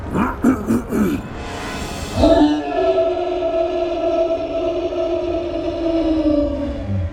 File:Big Notzilla roar.ogg
Big_Notzilla_roar.ogg